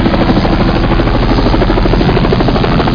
Amiga 8-bit Sampled Voice
chopper3.mp3